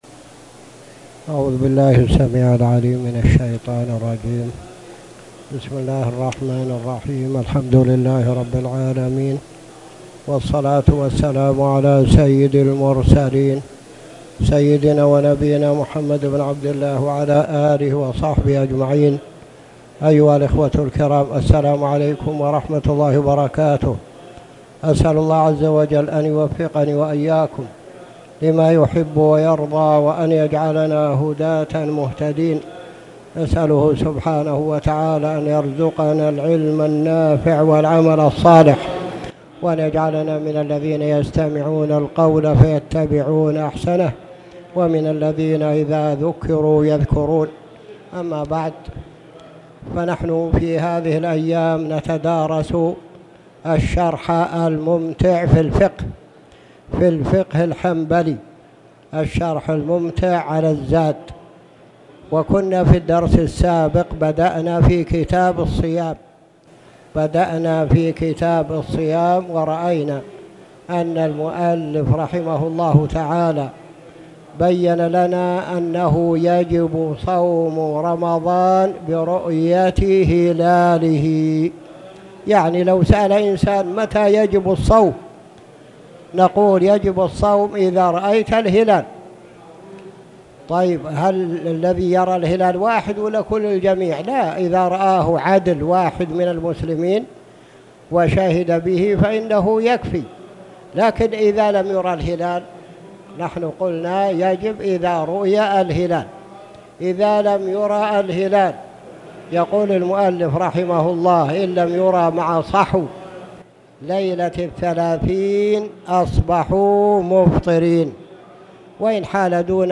تاريخ النشر ١٨ رجب ١٤٣٨ هـ المكان: المسجد الحرام الشيخ